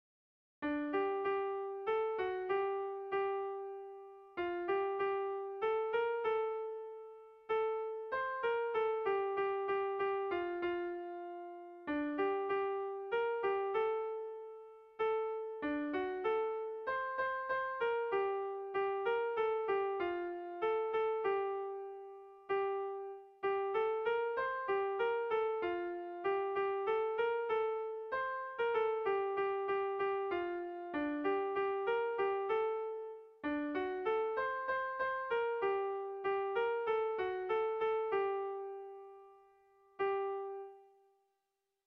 Kontakizunezkoa
Leitza < Leitzaldea < Iruñeko Merindadea < Nafarroa < Euskal Herria
Zortziko txikia (hg) / Lau puntuko txikia (ip)
ABDEBD